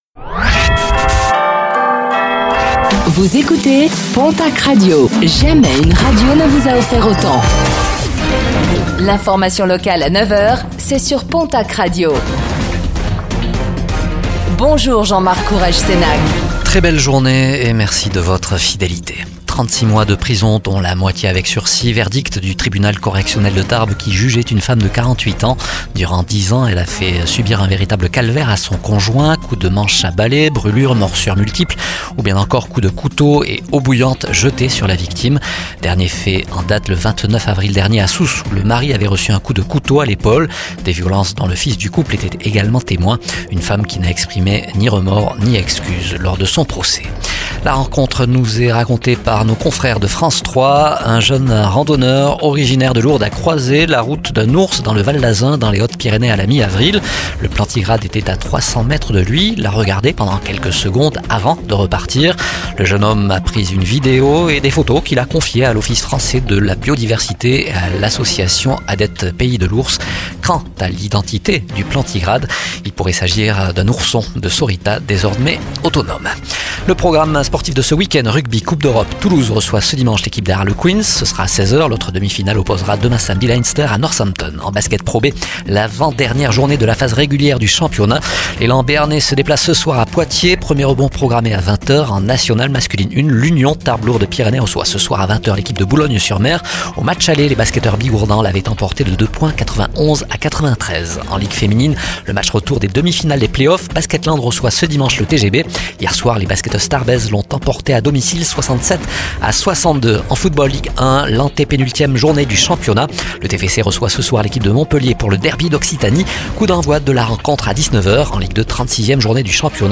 Infos | Vendredi 03 mai 2024